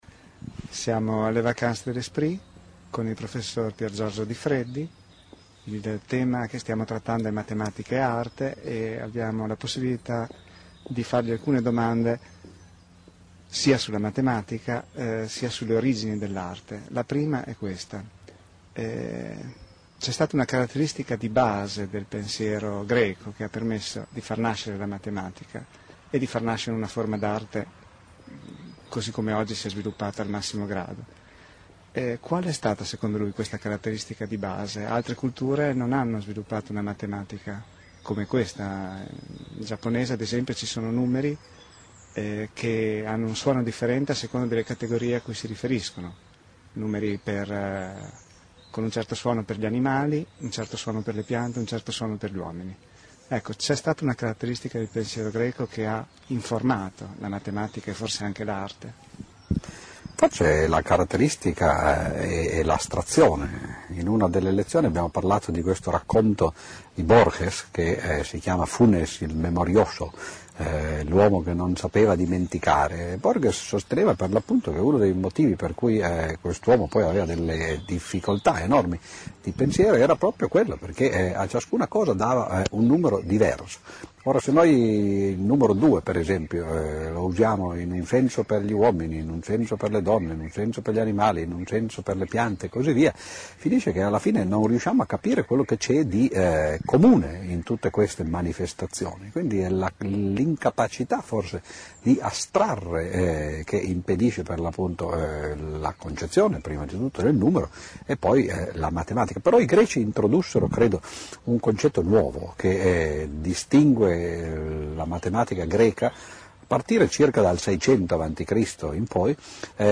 Intervista a Piergiorgio Odifreddi alle Vacances de l'Esprit 2007
Podcast delle vacances de l'esprit Intervista a Piergiorgio Odifreddi alle Vacances de l'Esprit 2007 Intervista esclusiva del Centro Studi ASIA al prof. Piergiorgio Odifreddi, matematico, saggista, divulgatore scientifico e storico della scienza italiano Clicca qui per ascoltare l'intervista Durata minuti 15:02